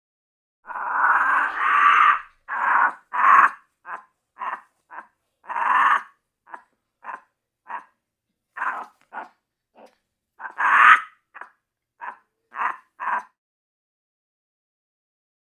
Monkey, Capuchin Growls, Chatter. Low Pitched, Scratchy Growl / Moans. Close Perspective.